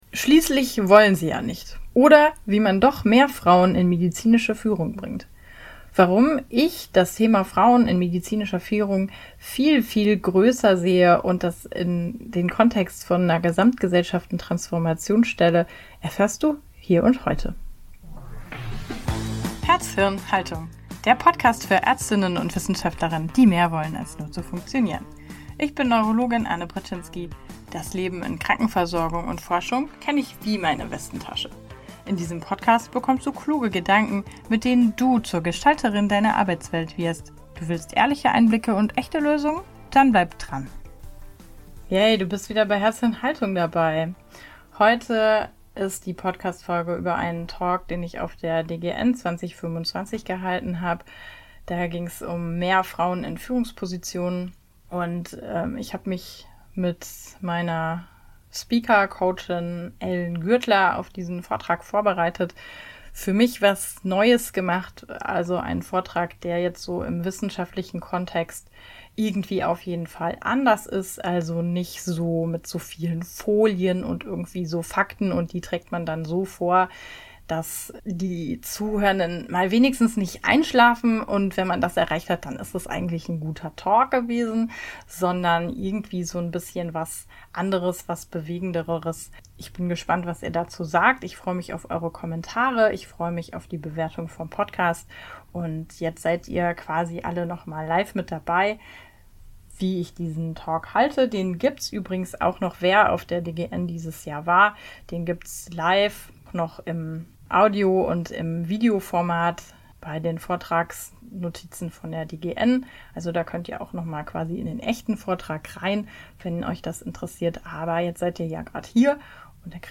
In dieser Folge von „herz.hirn.haltung.“ hörst du einen sehr persönlichen und politischen Talk, den ich auf der DGN 2025 gehalten habe.